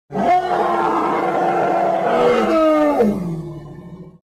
Download Dinosaur Roar sound effect for free.
Dinosaur Roar